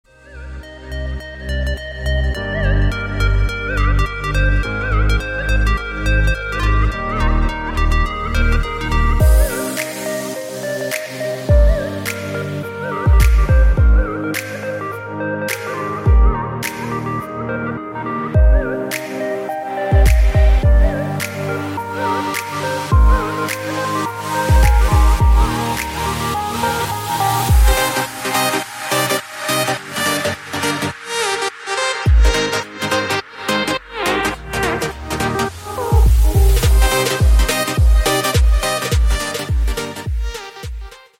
• Качество: 128, Stereo
атмосферные
dance
EDM
без слов
красивая мелодия
энергичные
Стиль: dance i edm.